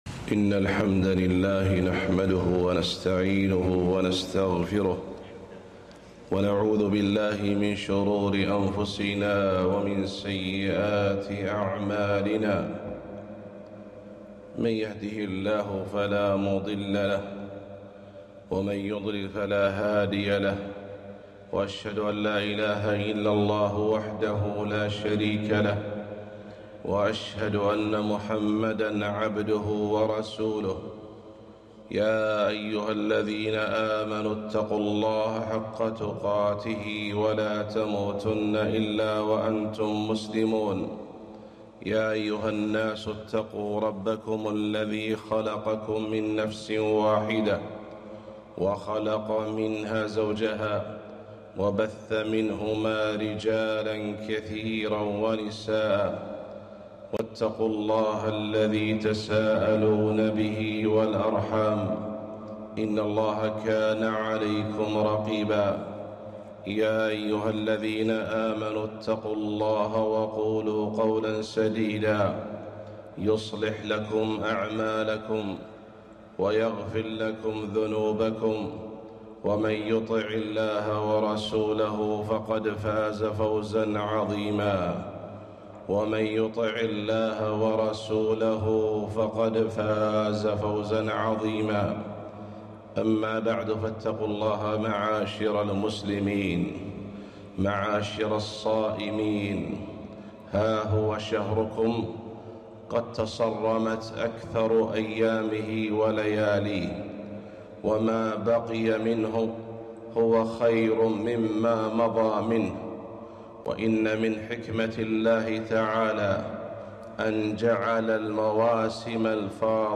خطبة - وأقبلت ليالي العشر